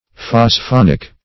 Search Result for " phosphonic" : The Collaborative International Dictionary of English v.0.48: Phosphonic \Phos*phon"ic\ (f[o^]s*f[o^]n"[i^]k), a. [Phosphoric + sulphonic.]
phosphonic.mp3